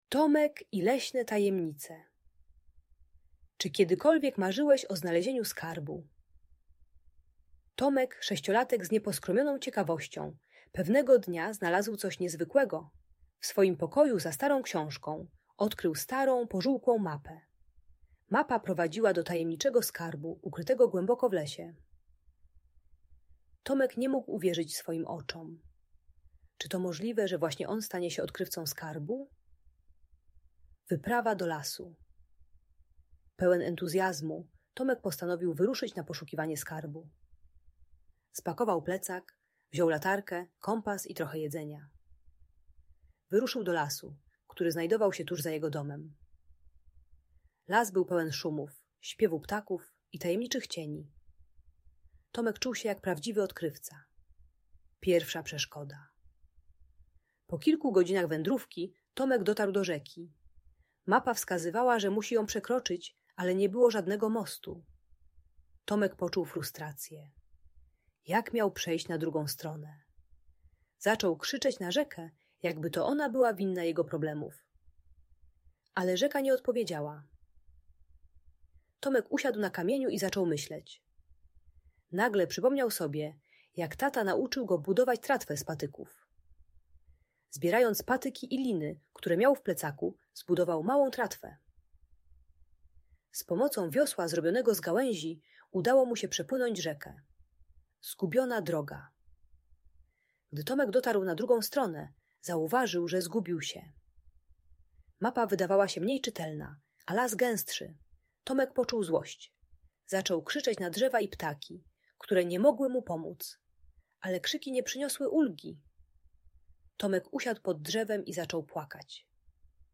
Tomek i Leśne Tajemnice - Fascynująca bajka dla Dzieci - Audiobajka